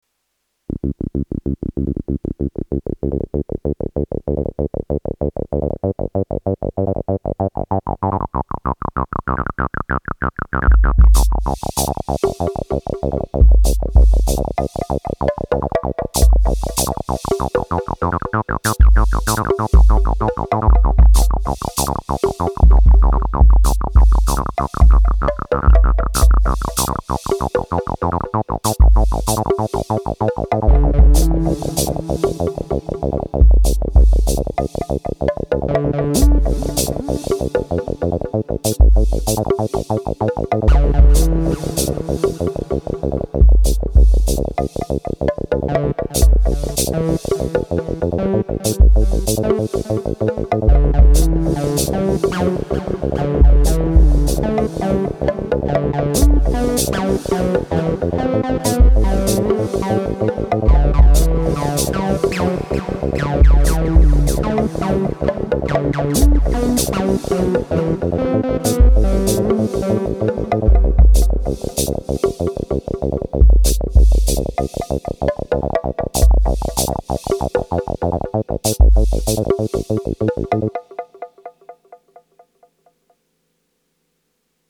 AN1X Acid